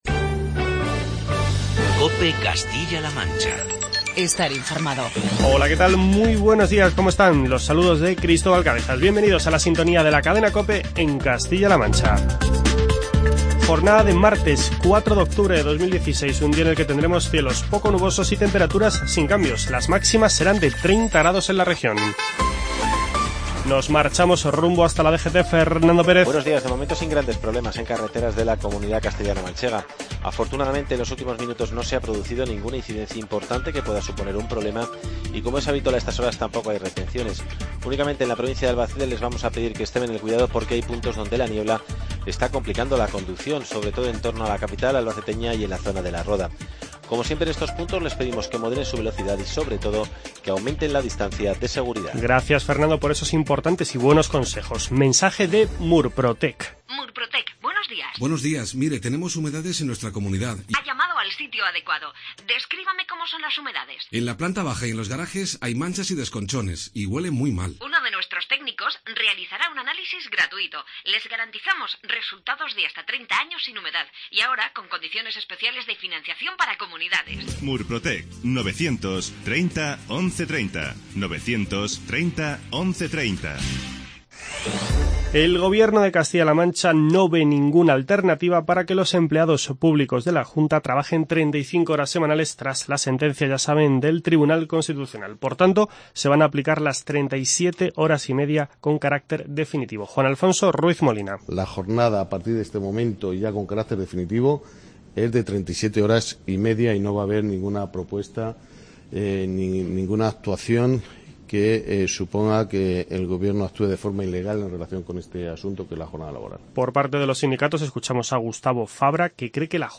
Declaraciones del titular de Hacienda en la sintonía de la Cadena COPE en Castilla-La Mancha.